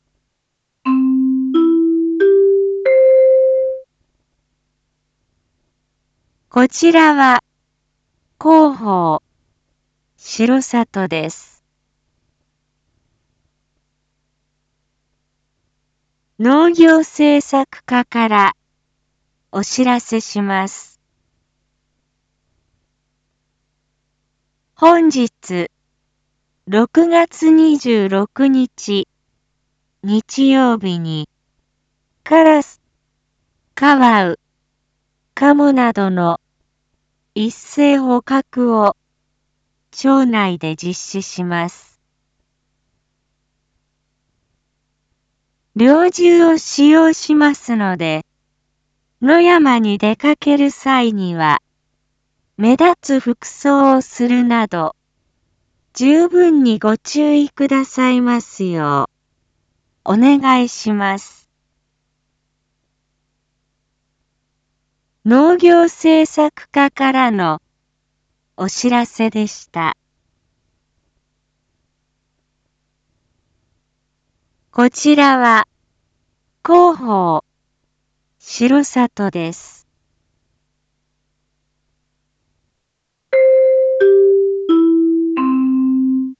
一般放送情報
Back Home 一般放送情報 音声放送 再生 一般放送情報 登録日時：2022-06-26 07:01:27 タイトル：R4.6.26 7時放送分 インフォメーション：こちらは広報しろさとです。